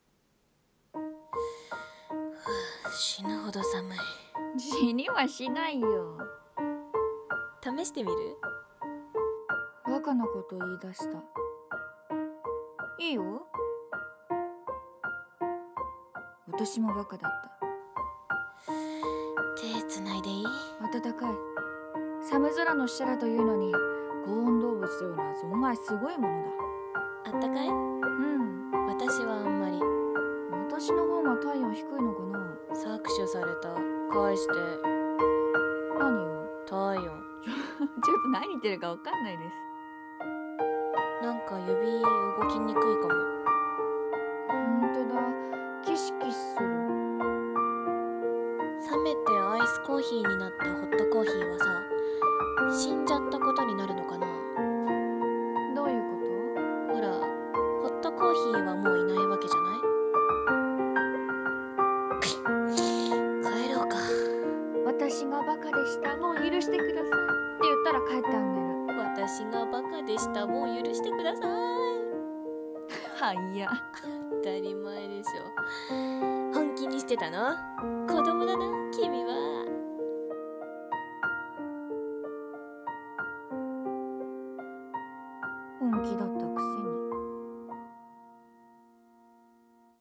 【声劇台本】百合心中